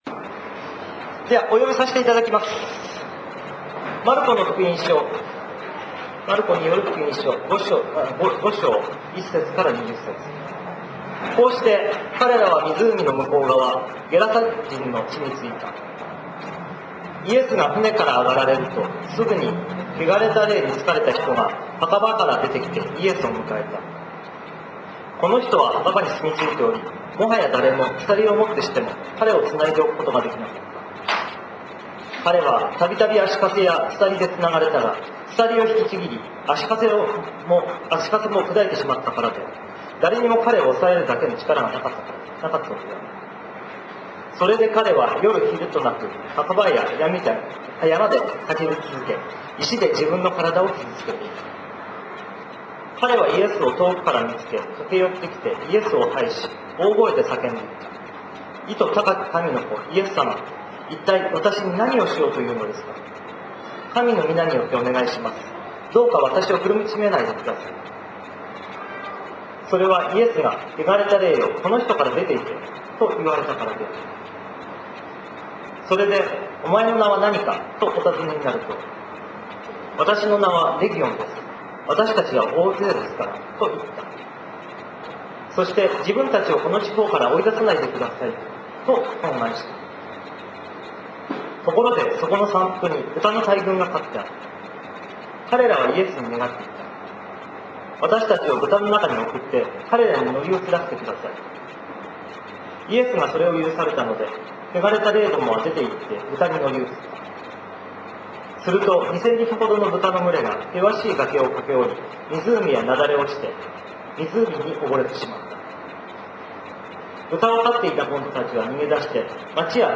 礼拝メッセージ集 - タイ聖書福音教会